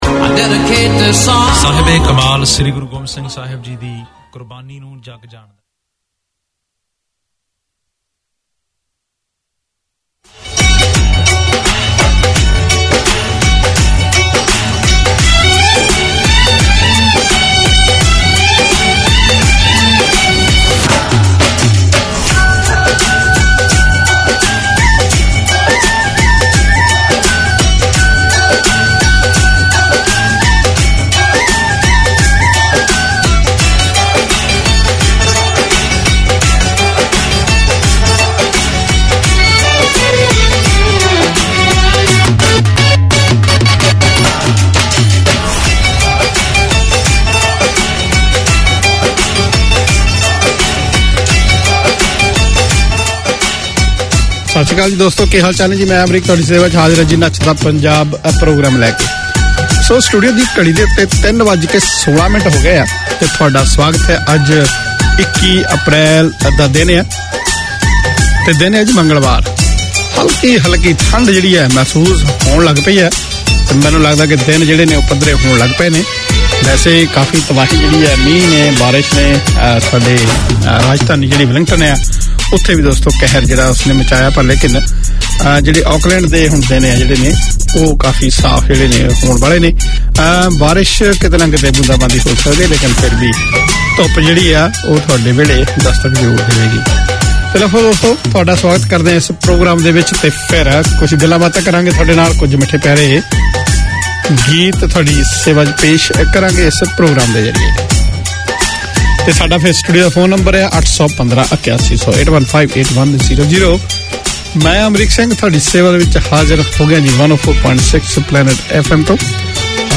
Community Access Radio in your language - available for download five minutes after broadcast.
Mehak Wattan Di No shows scheduled this week Community magazine Language